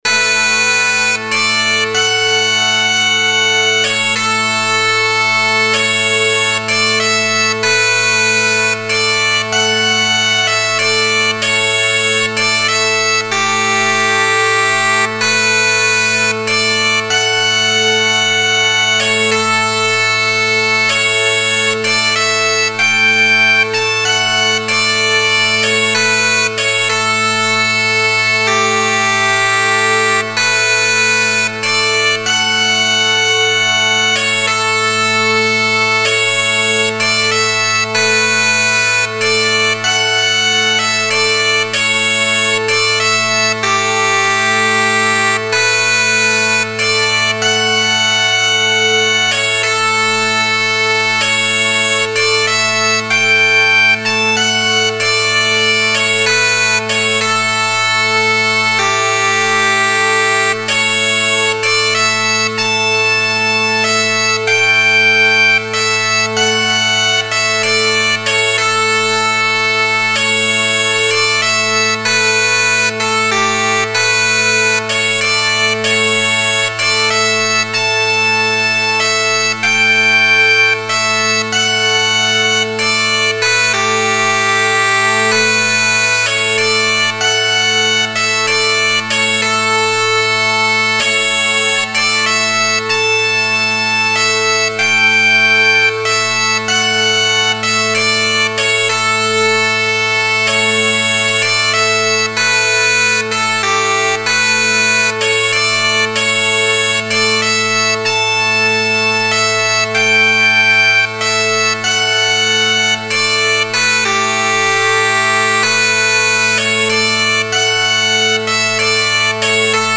for 2 violins, bagpipes, piano, organ and drums